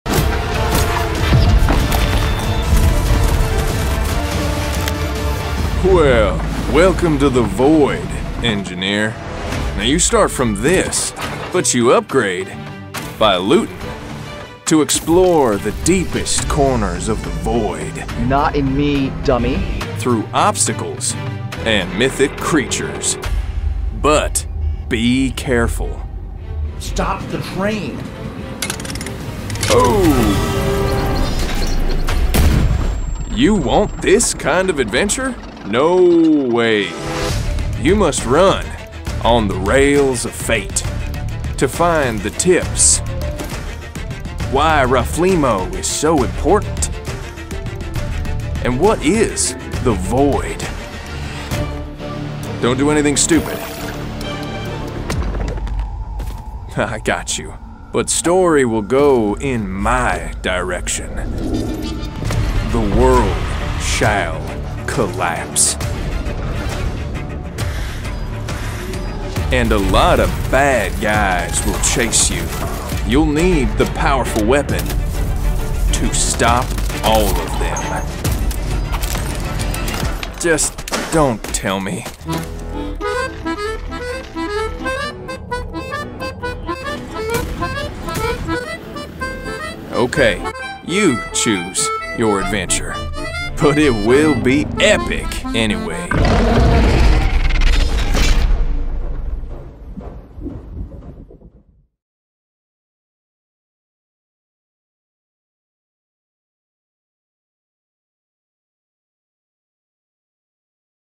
Charismatic male VA with voice smoother than a glass of Pendleton on the rocks.
Void Train Video Game Trailer